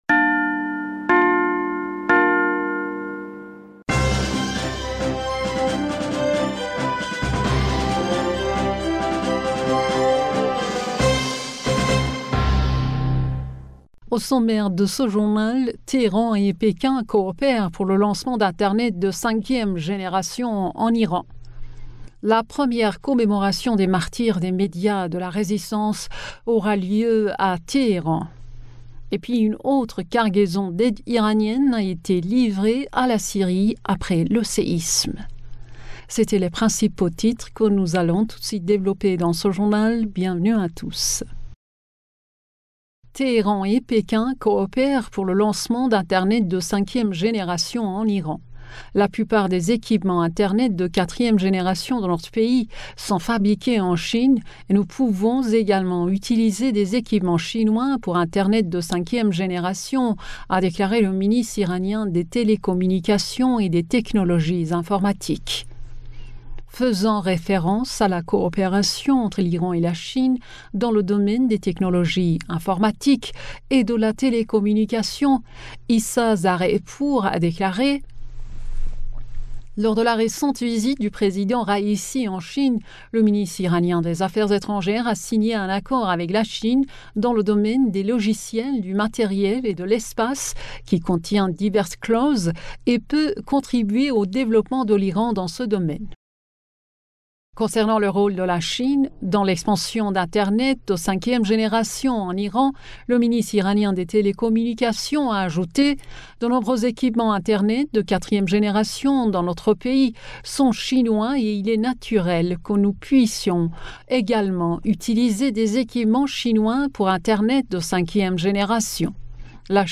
Bulletin d'information du 26 Février